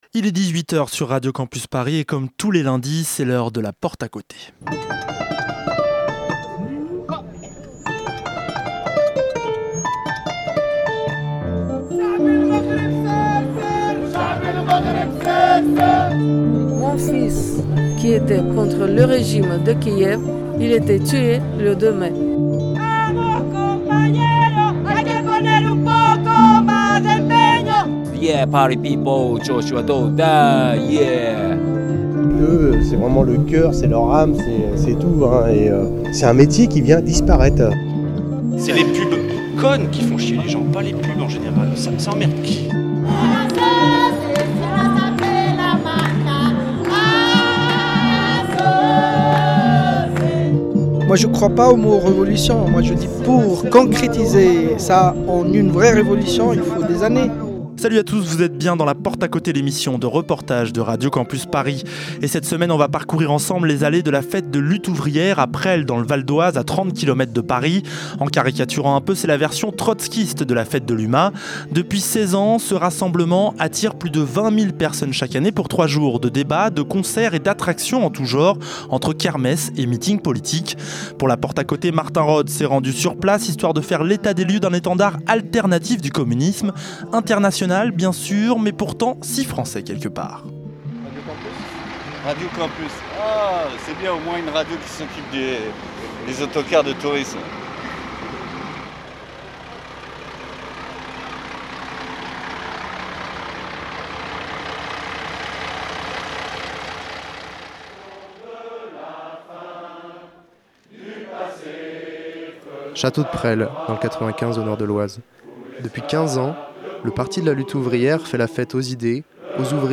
A La Porte à Côté nous avons laissé traîner notre micro entre les stands pour prendre le pouls de ce mouvement alternatif au communisme.